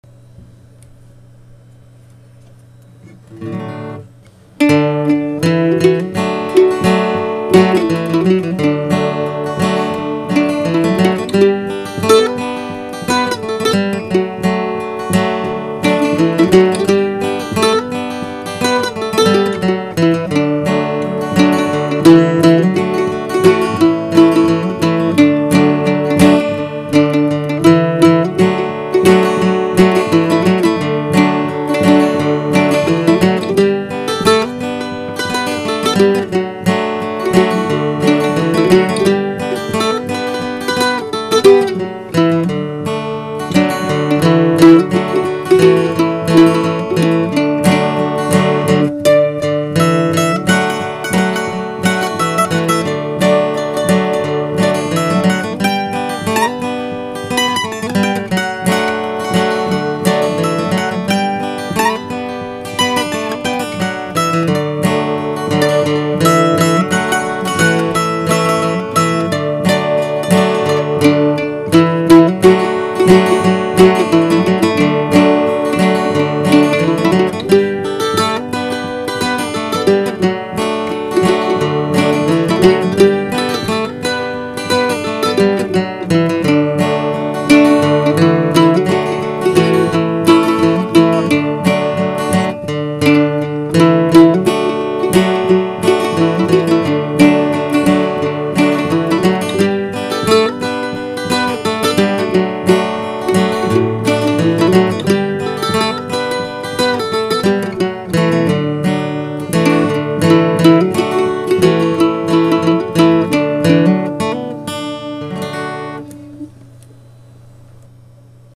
Other Duets; Vocals: